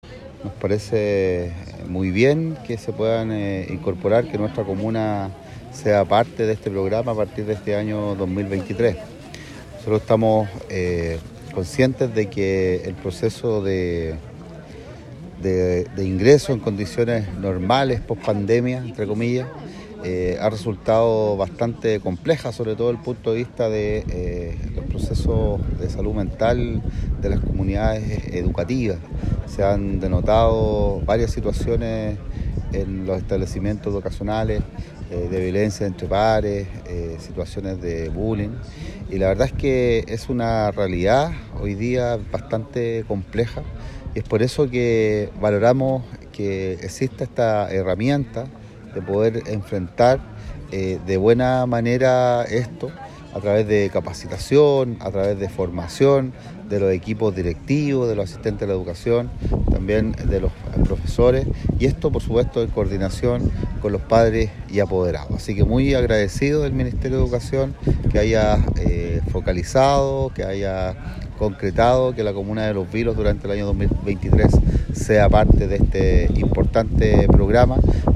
Respecto a la inclusión de la comuna de Los Vilos al programa “A convivir se aprende”, su alcalde Christian Gross Hidalgo manifestó que
2.-Christian-Gross-Hidalgo-Alcalde-de-Los-Vilos_.mp3